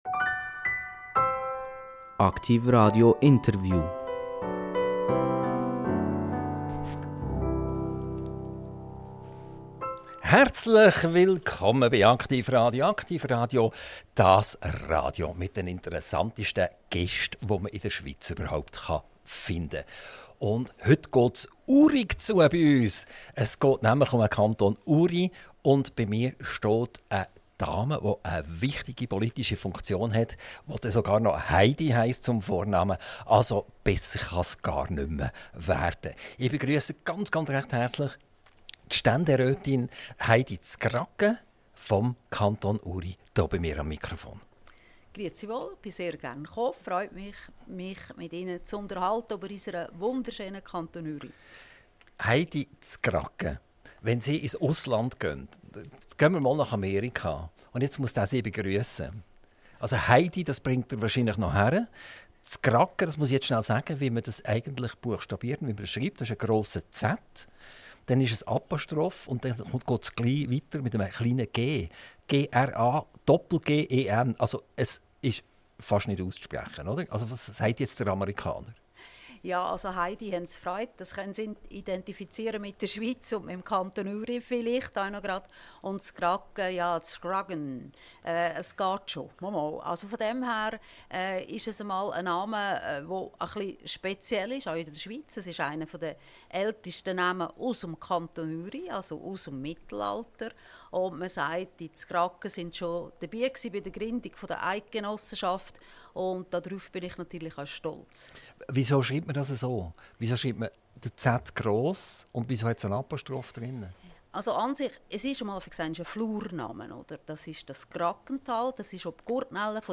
INTERVIEW - Heidi Z'graggen - 08.10.2025 ~ AKTIV RADIO Podcast